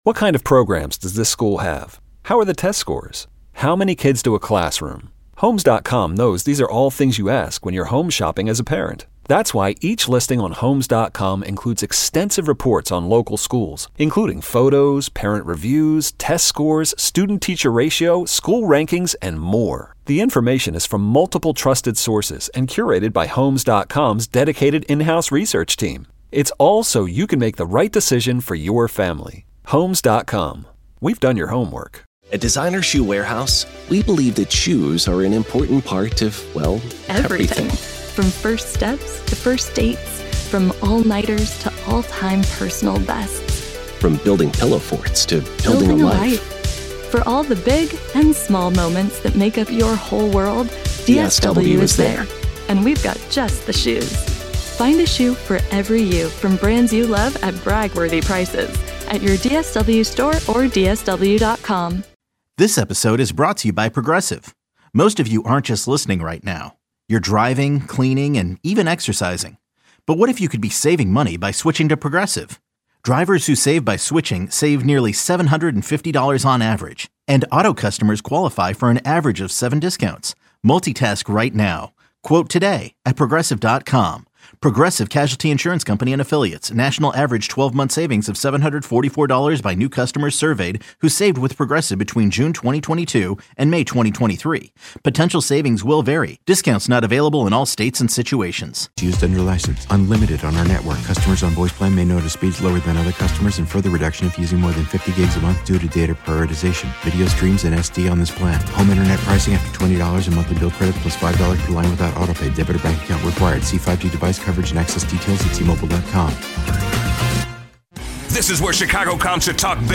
Chicago sports talk
Catch the show live Monday through Friday (2 p.m. - 6 p.m. CT) on 670 The Score, the exclusive audio home of the Cubs and the Bulls, or on the Audacy app.